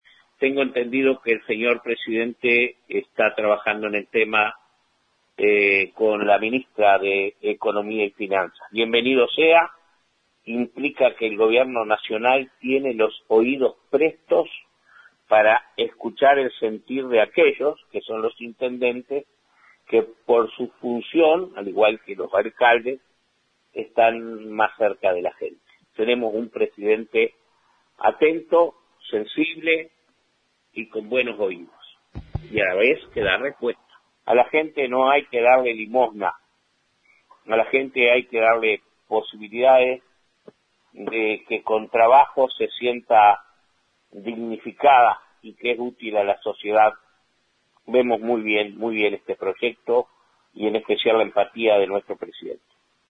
En diálogo con 970 Noticias, Carmelo Vidalín señaló: “Tenemos un presidente atento, sensible y con buenos oídos. A la gente no hay que darle limosna, hay que darle posibilidades de que con trabajo se sienta dignificada y que es útil a la sociedad.”